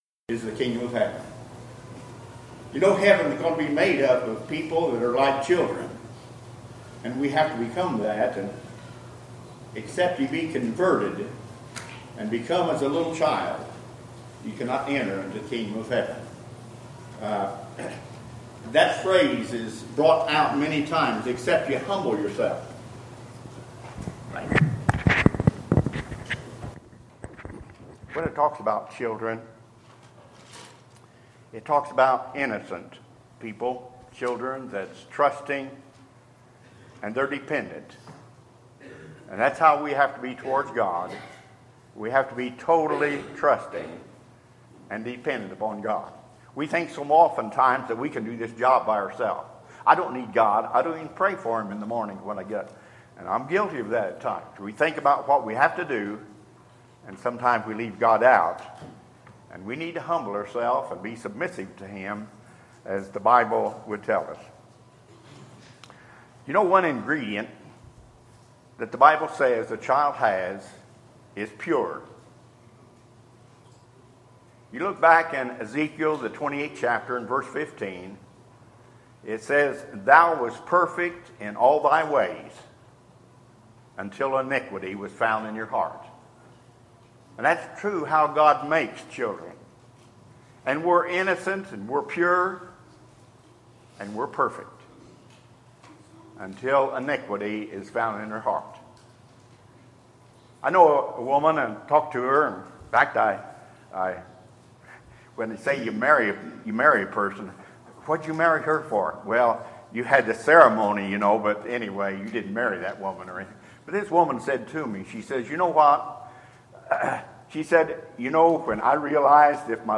Sermons, September 18, 2016